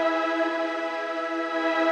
SaS_MovingPad05_125-E.wav